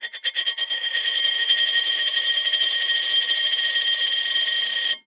Coin Spinning Sound
household
Coin Spinning